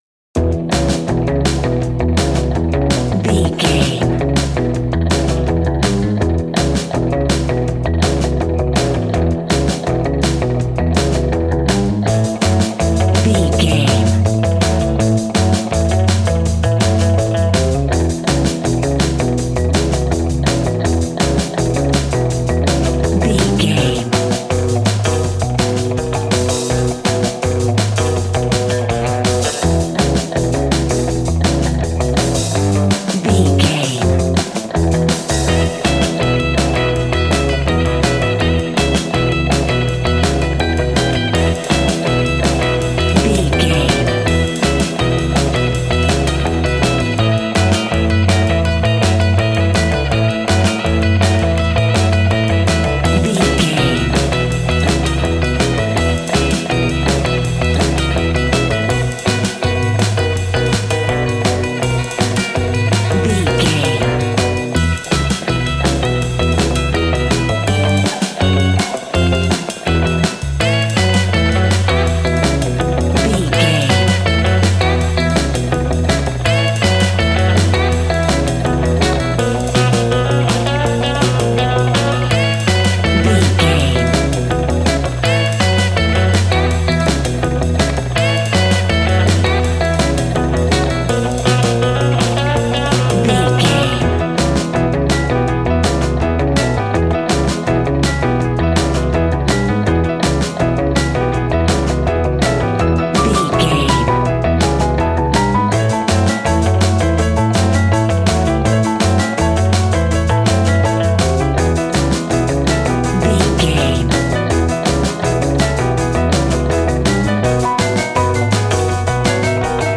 Ionian/Major
cool
uplifting
bass guitar
electric guitar
drums
60s
cheerful/happy